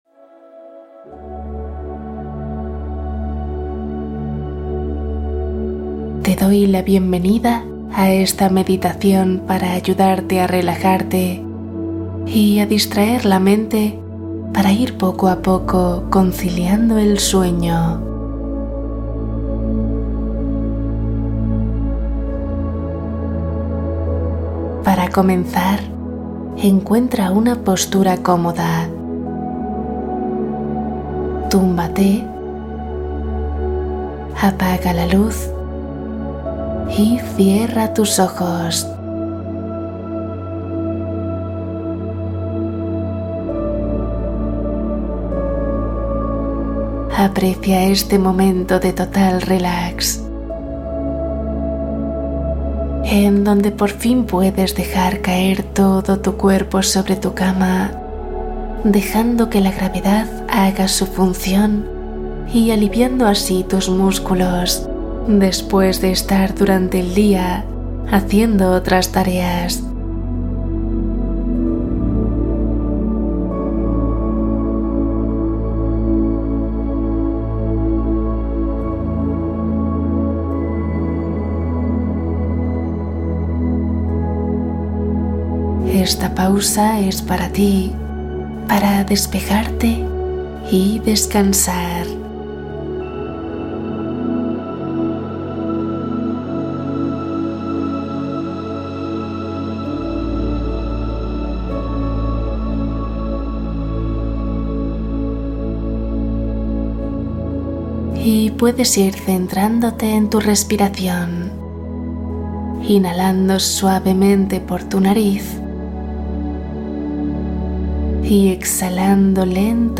Meditación de la mañana ❤ 10 minutos para claridad y energía